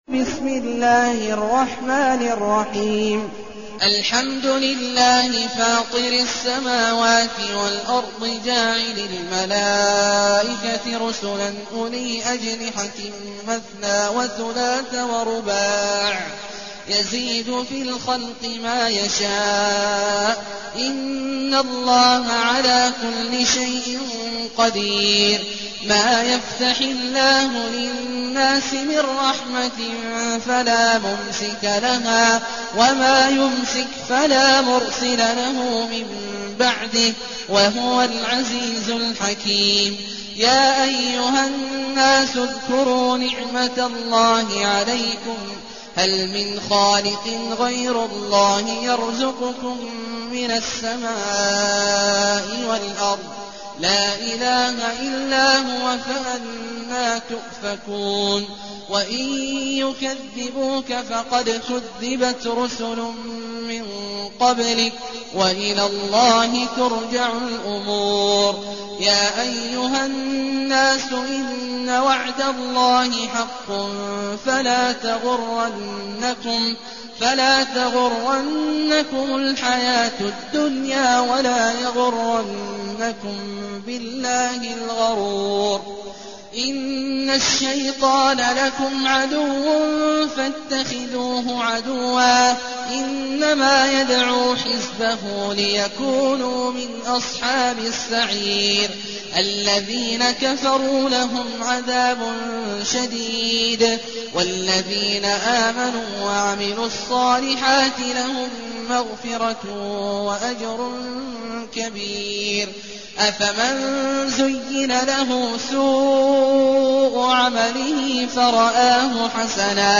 المكان: المسجد النبوي الشيخ: فضيلة الشيخ عبدالله الجهني فضيلة الشيخ عبدالله الجهني فاطر